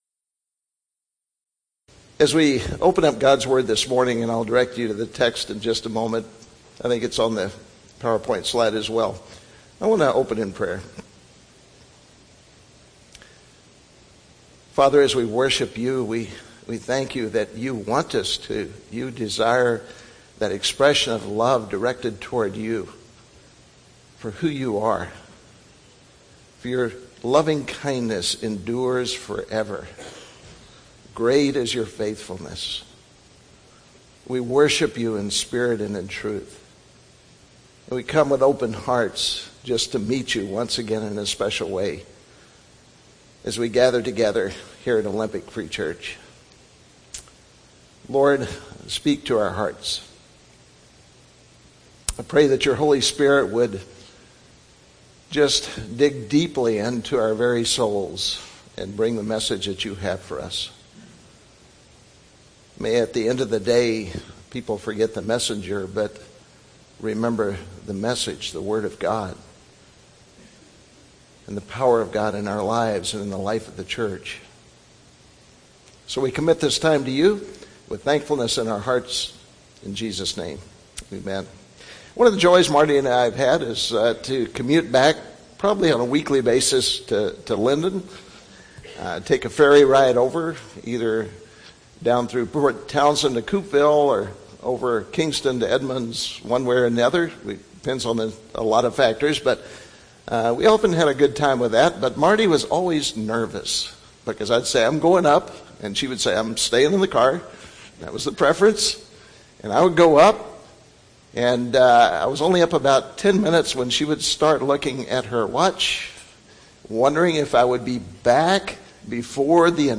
2017 Sermons